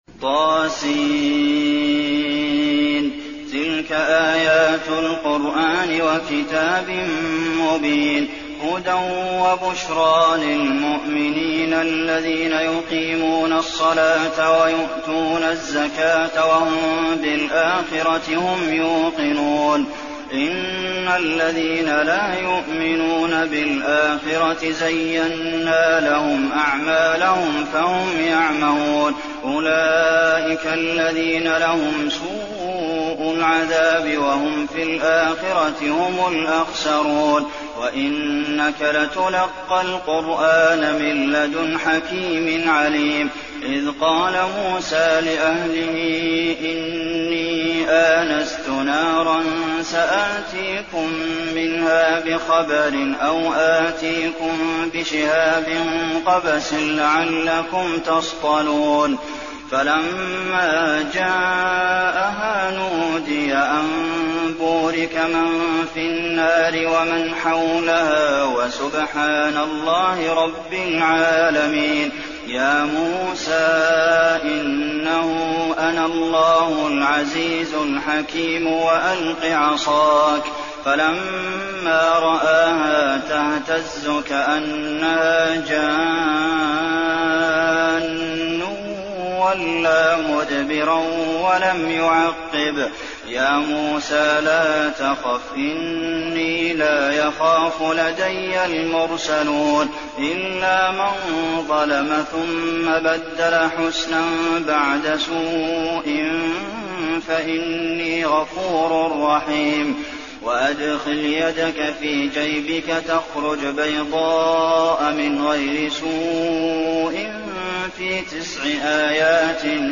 المكان: المسجد النبوي النمل